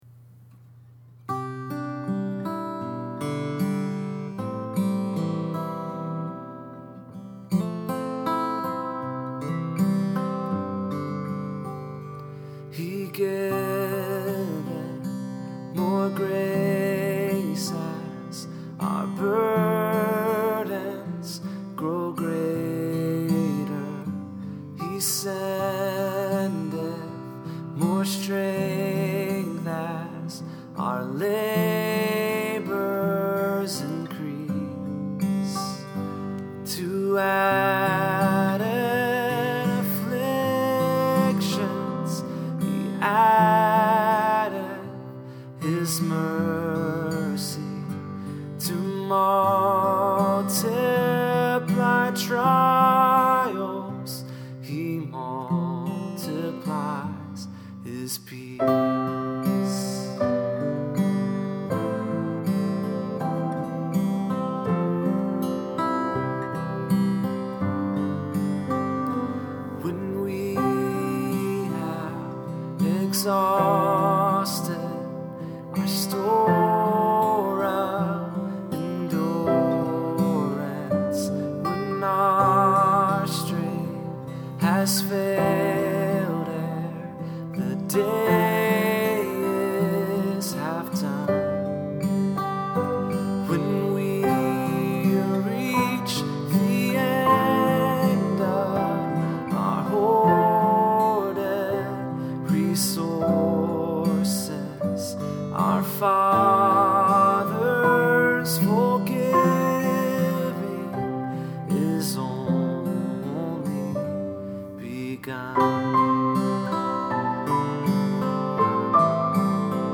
I’m sharing the rough demo with you, as well as a chord chart, since my guess is that if your congregation doesn’t know this hymn, they might be affected by the truth it proclaims just like mine was.